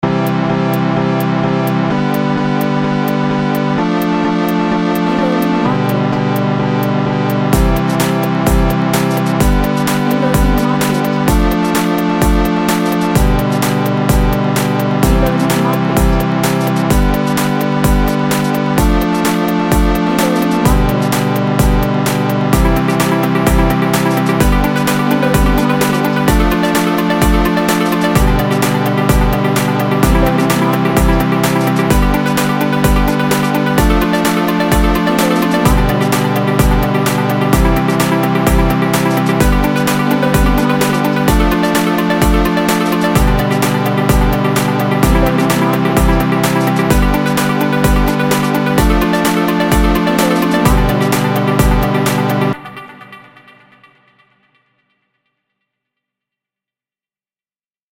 A track with lots of energy and synth melody
Energetic